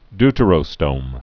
(dtə-rō-stōm)